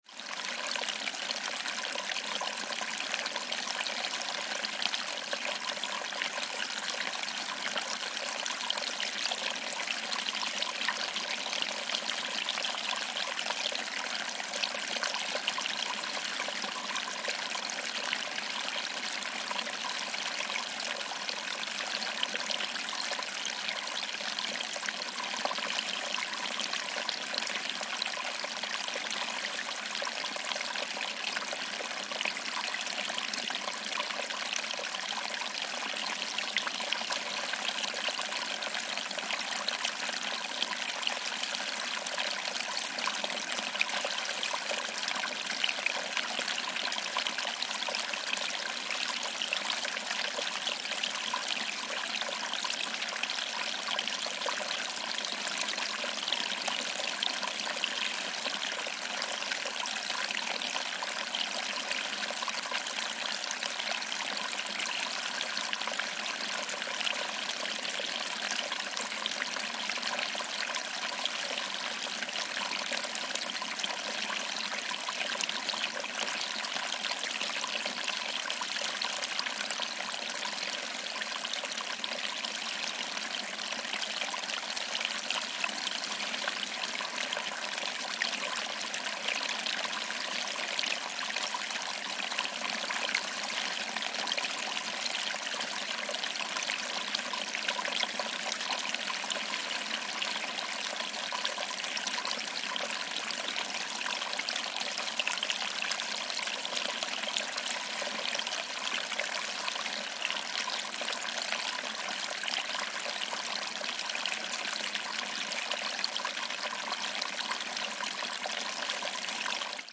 Звуки водопада
Звук пенистого водопада: Шепот маленького водоворота, Треск водного пузыря, Журчание ручейка